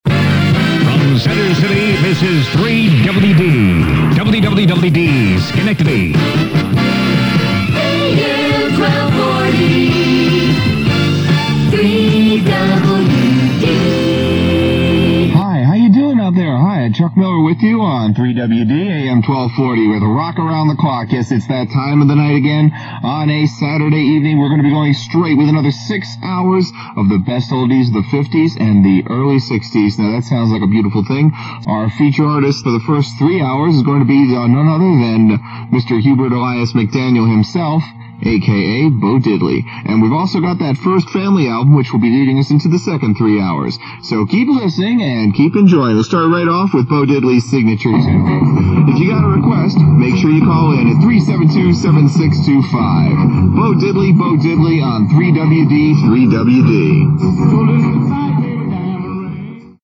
The sound quality is poor, and I don’t remember if I recorded them just to hear how I sounded back in the day, or if I thought by putting them together I could create an audio resume for a future radio job.
And unlike my time with WHCL, I actually have some airchecks of my work available for you to hear how I sounded back in the day.